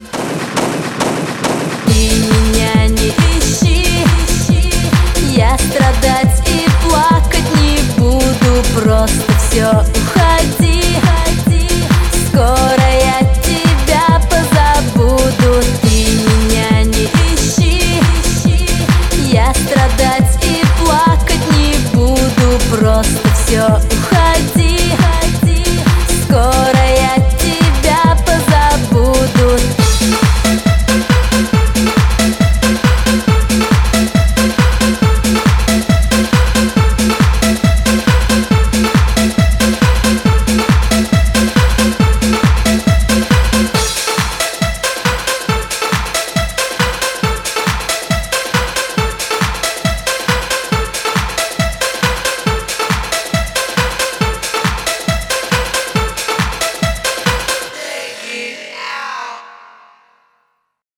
• Качество: 128, Stereo
громкие
Eurodance
europop